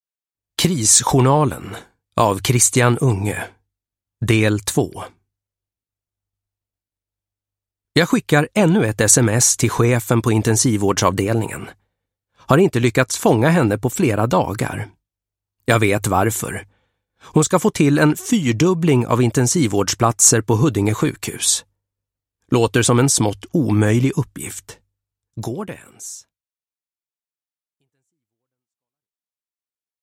Krisjournalen - 2 - Akutkliniken rustar – Ljudbok – Laddas ner